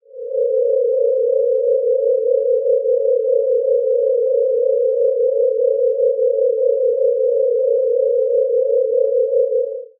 74_draconis_sonification.wav